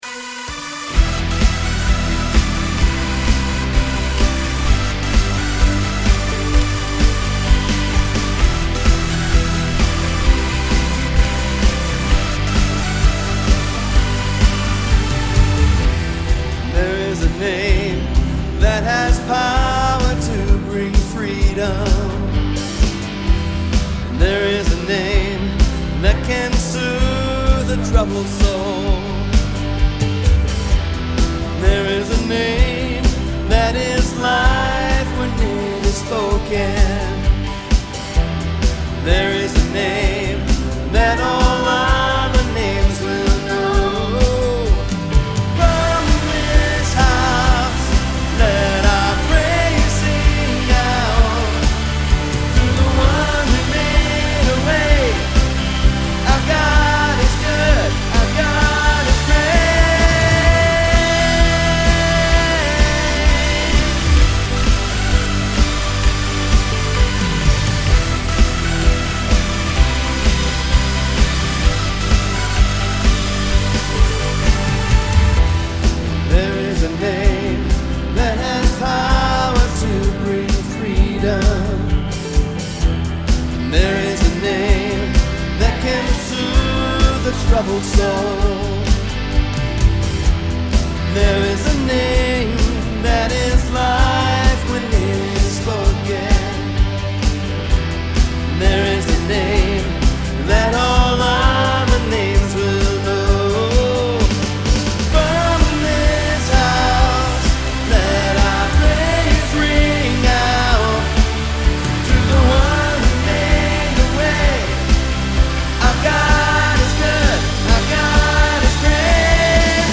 I created this video clip and selected these audio clips to provide you with a fairly raw sound, without post-production polish and editing.
For interest, we included a small choir, which you'll hear come into play toward the end. We also utilized a couple of loop channels to free musicians to play their favorite parts.